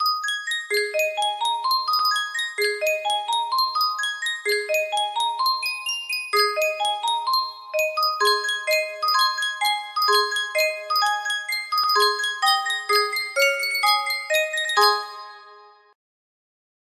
Sankyo Music Box - And the Green Grass Grew All Around YRO music box melody
Full range 60